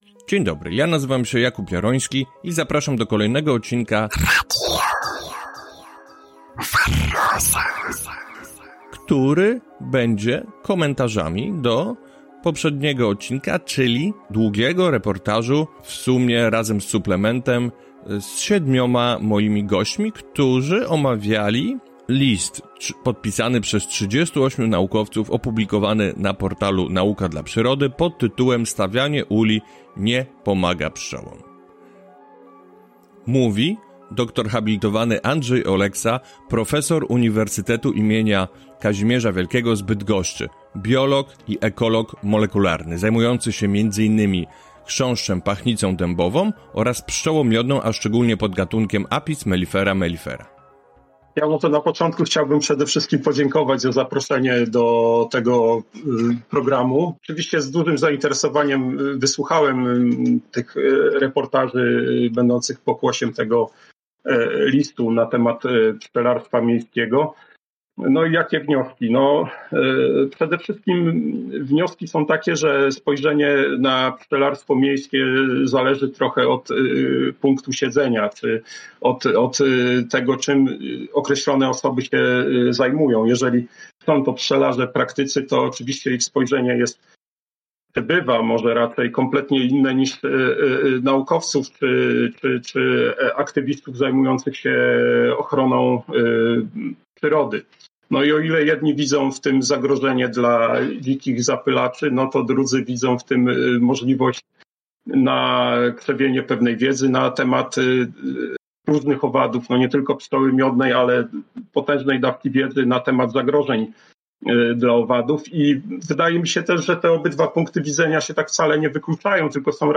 Pobieranie – Pszczoły w mieście a pasieki - dyskusja 3/3